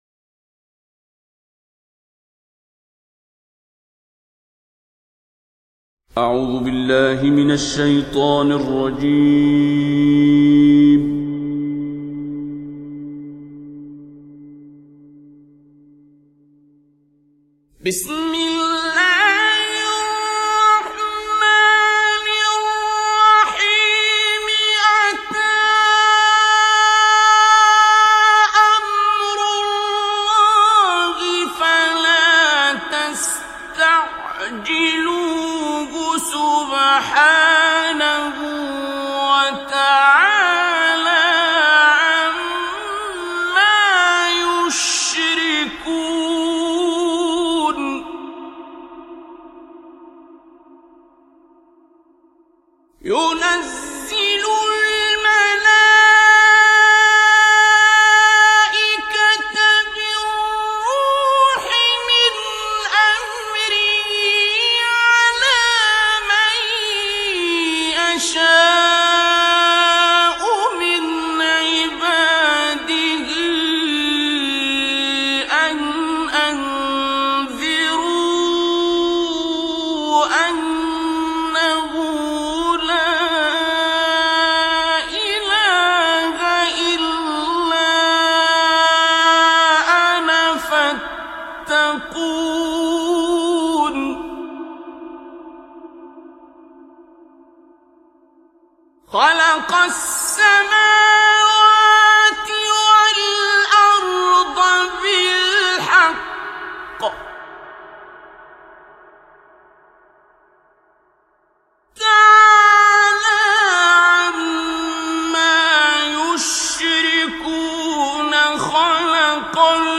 الختمة المجودة الأولى للشيخ عبد الباسط ( المصحف المجود النادر ) 1961م